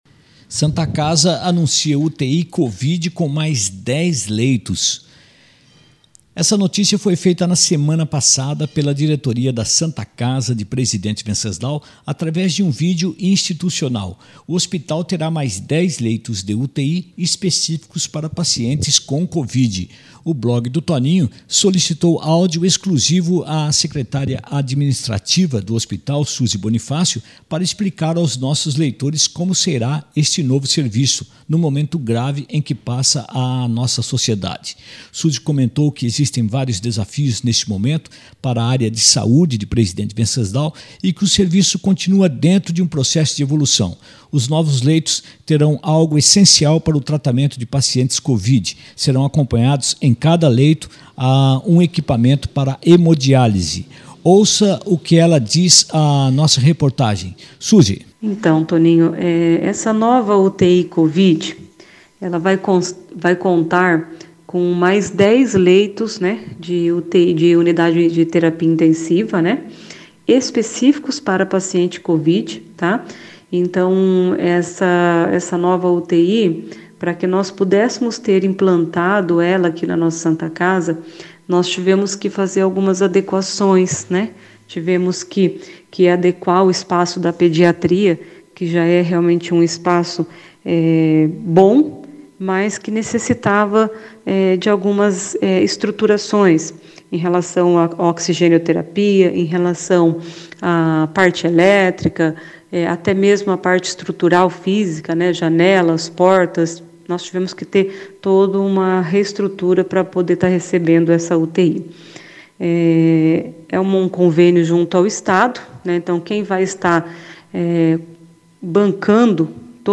Os novos leitos terão algo essencial para o tratamento de pacientes Covid, serão acompanhadas por um equipamento para hemodiálise. Ouça o que ela diz à nossa reportagem.